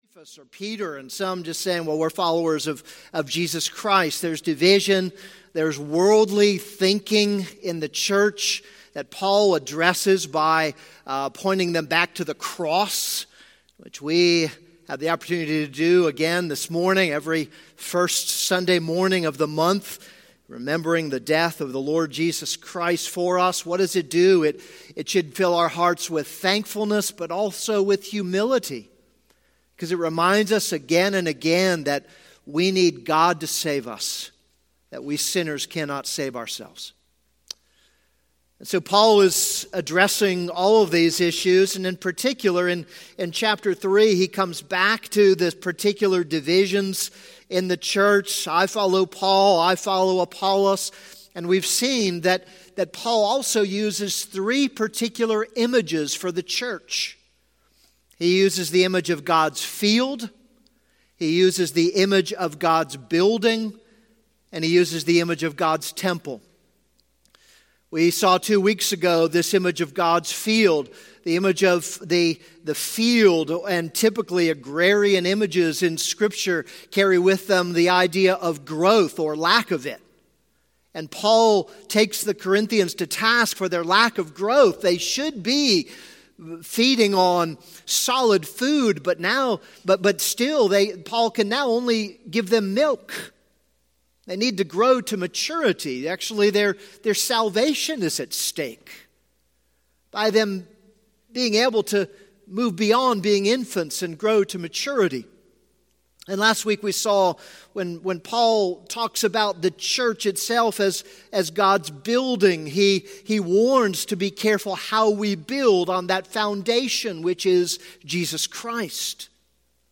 This is a sermon on 1 Corinthians 3:16-23.